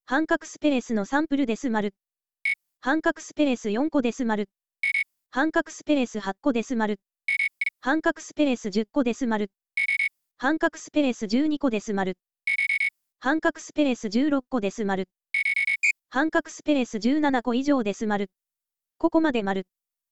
読み上げサンプル_space16
また、4個づつスピードをアップして、インデントをわかりやすくしました。